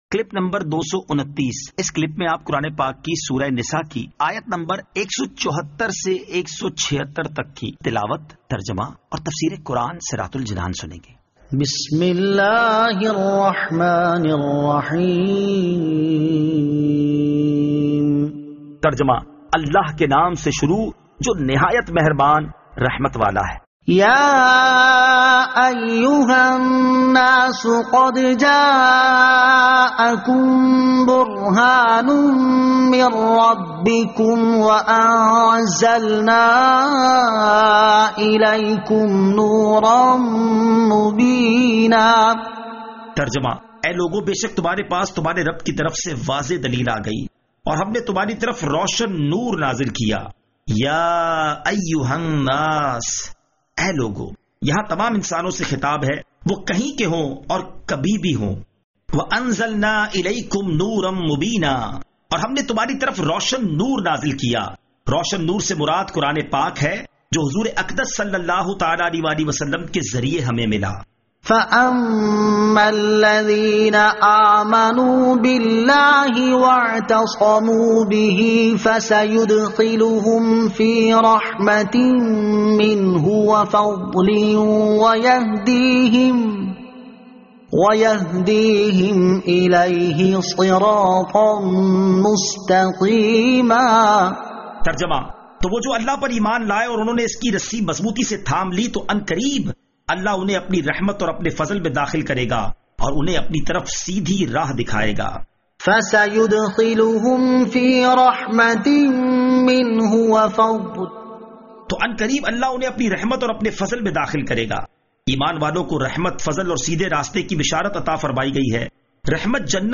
Surah An-Nisa Ayat 174 To 176 Tilawat , Tarjama , Tafseer